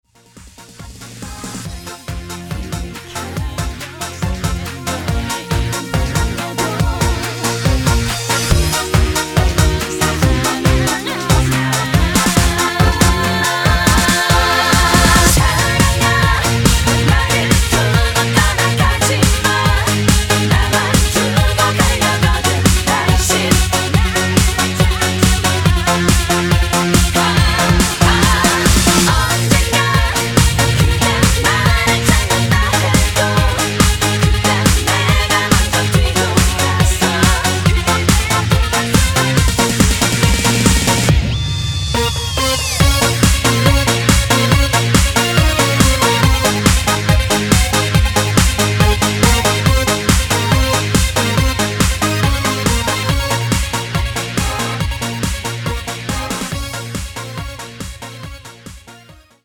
음정 원키 3:39
장르 가요 구분 Voice MR